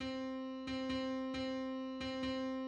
3. Long-short-short (dactyl)
3. Dotted quarter, eighth, quarter (barred in 6